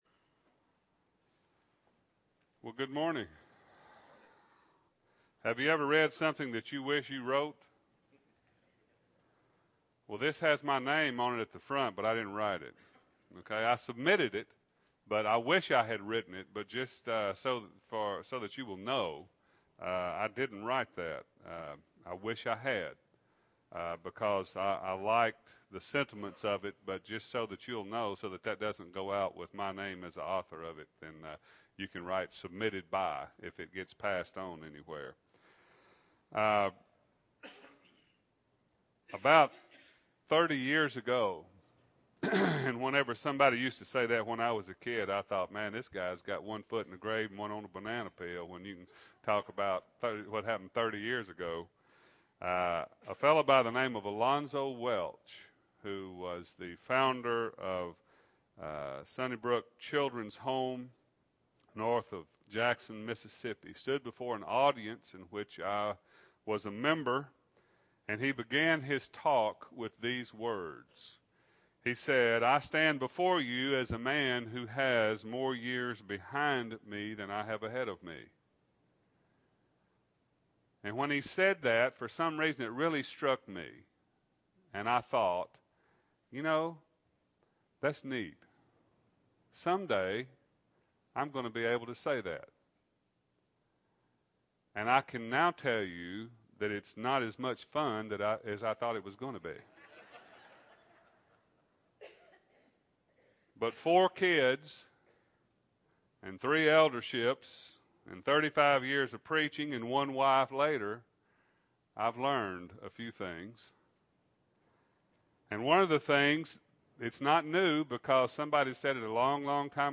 2008-01-27 – Sunday AM Sermon – Bible Lesson Recording